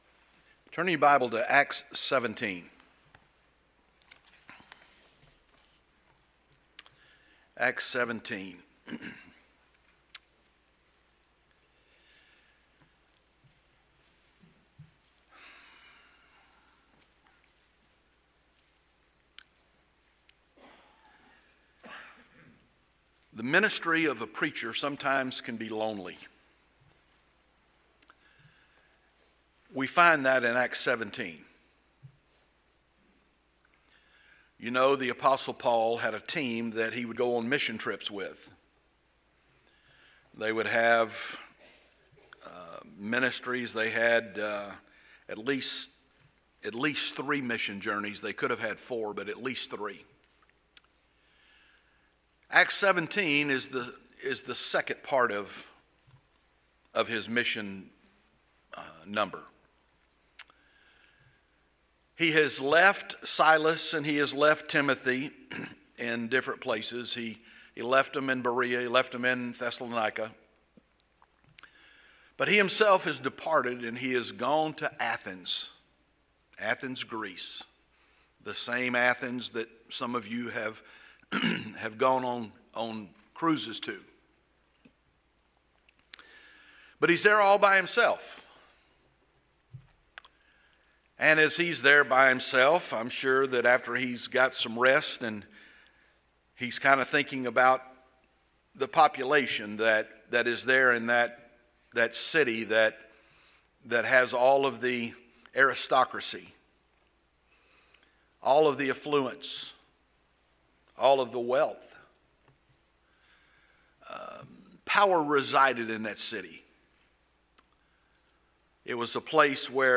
What Happens at the End of the Sermon? | Corinth Baptist Church